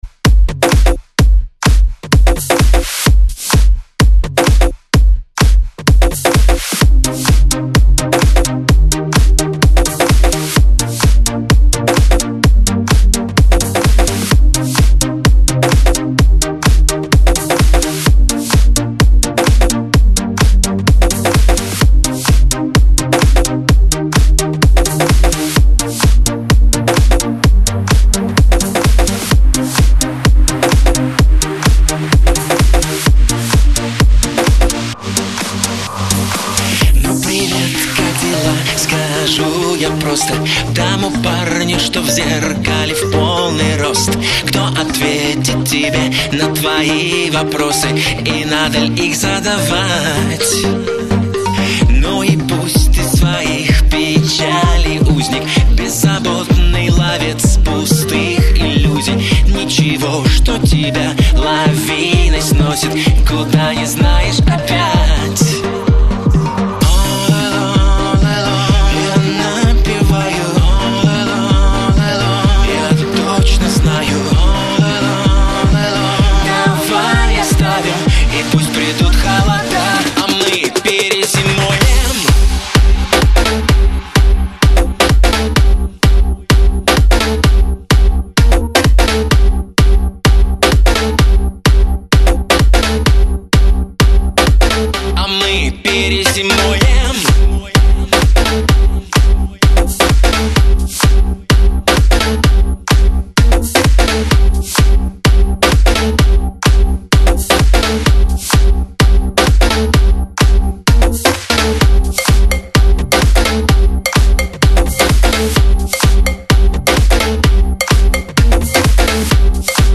Стиль: House
Позитив | Энергия | Чувство | Ритм | Стиль | Движение